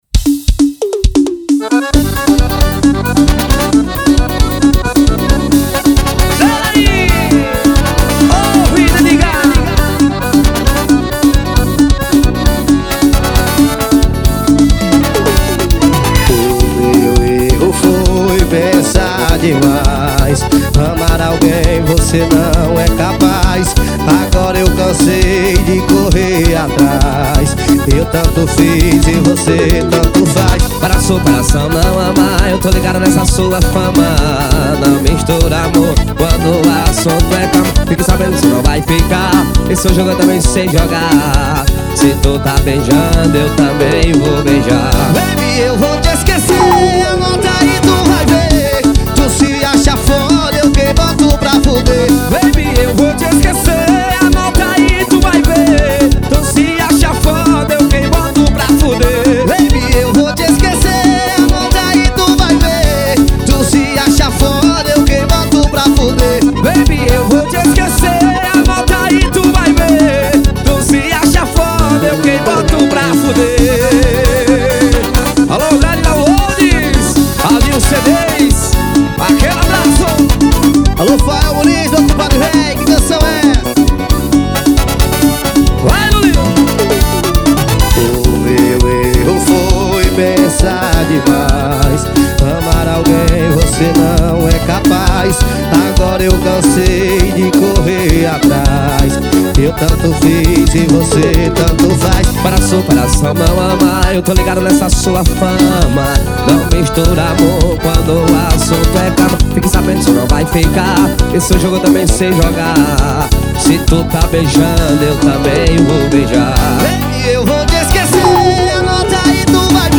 2024-02-14 18:15:46 Gênero: Forró Views